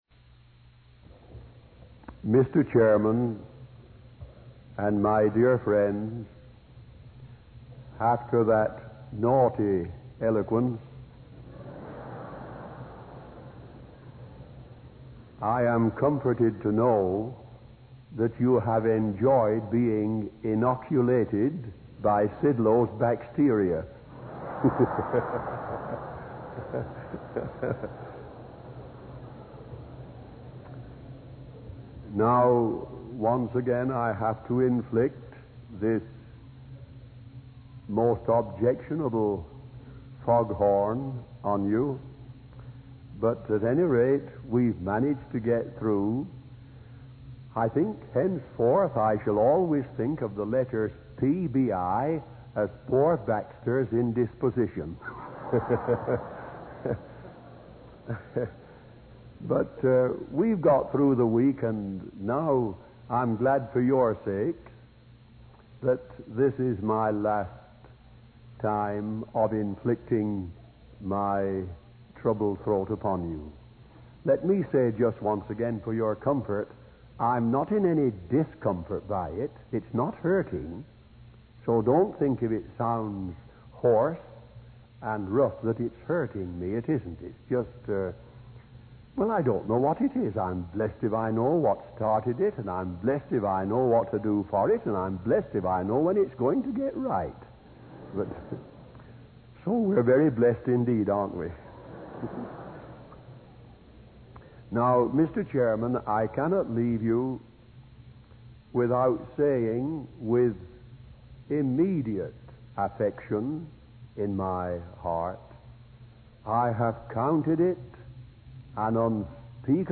In this sermon, the speaker discusses the concept of sanctification and its importance in our lives. He compares our physical bodies to the outer court of the tabernacle, which can be taken down and wrapped up for travel without affecting the sanctuary.